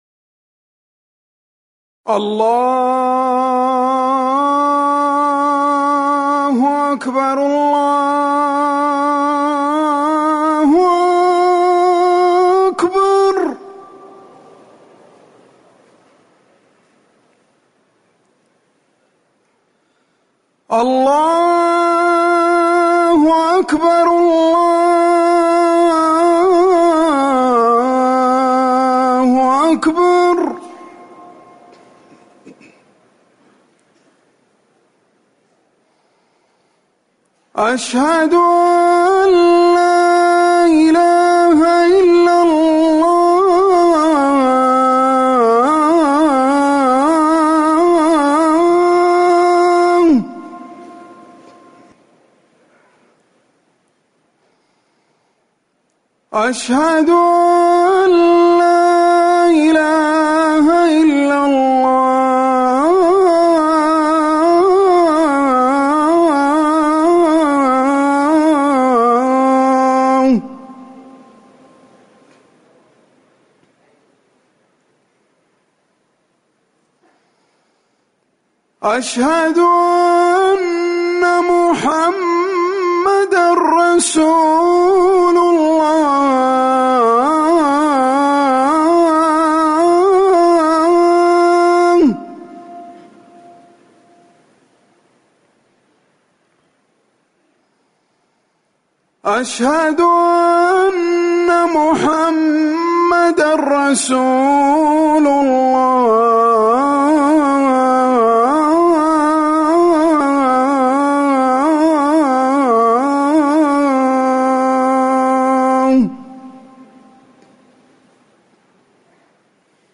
أذان المغرب
المكان: المسجد النبوي